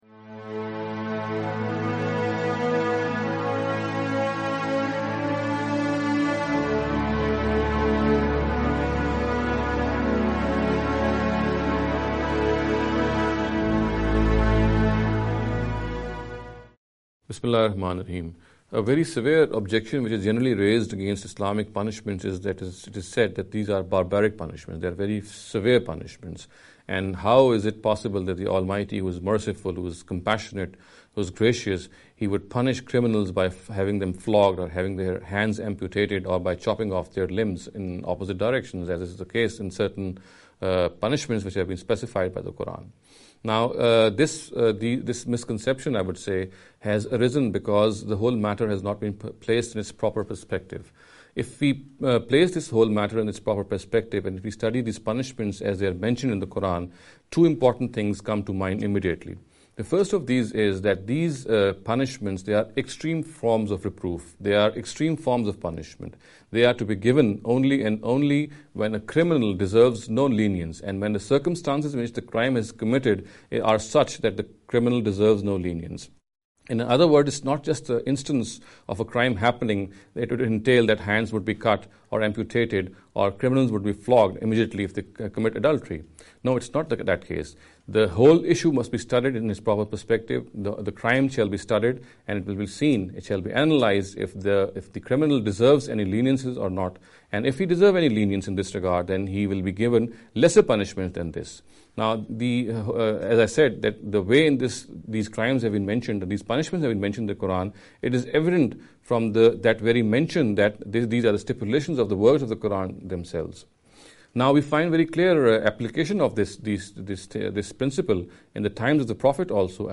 This lecture series will deal with some misconception regarding the Islamic Punishments. In every lecture he will be dealing with a question in a short and very concise manner. This sitting is an attempt to deal with the question 'Are Islamic Punishment Barbaric?’.